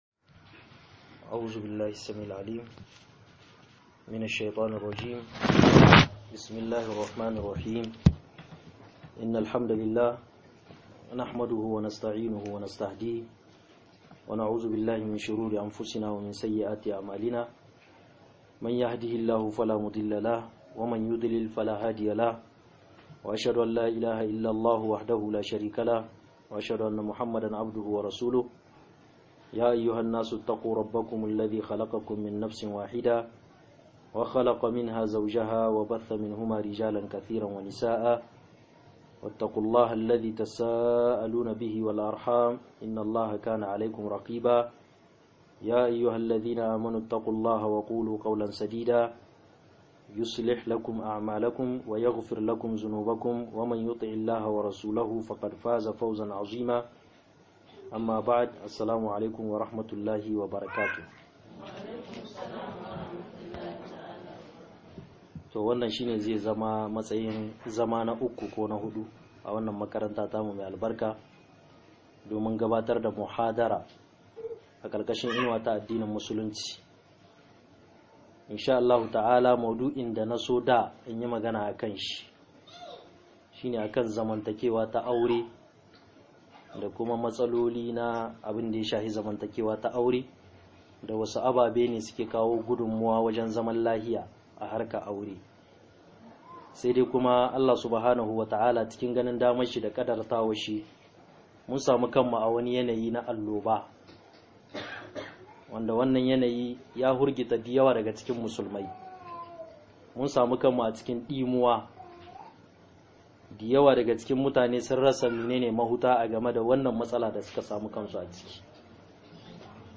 Kariya-daga-Annoba - MUHADARA